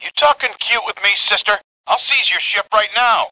―Viraxo 54 security threatens Aeron Azzameen aboard the disguised Selu moments before the craft is disabled — (audio)